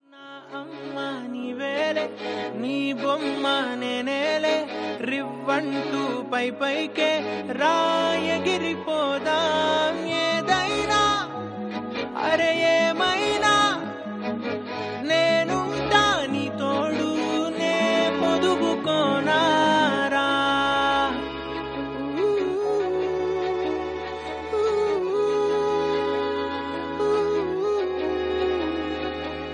romantic ringtone download | love song ringtone